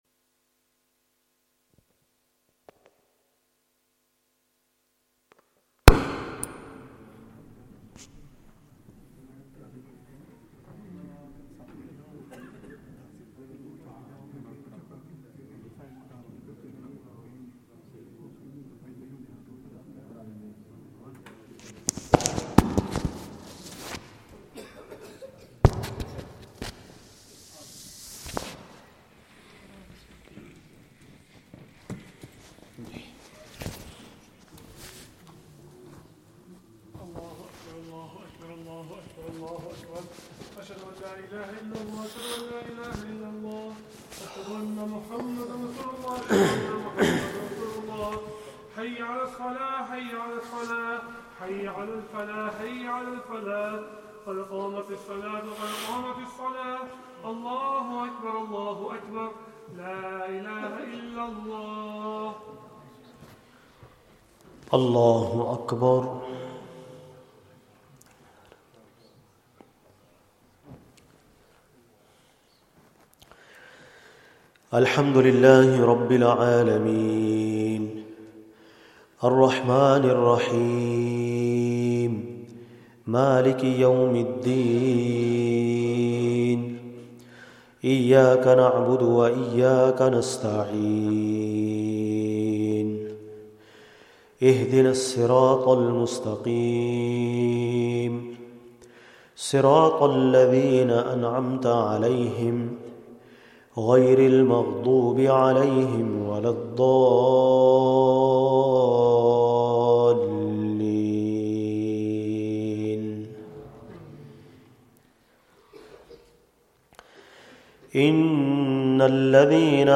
Taraweeh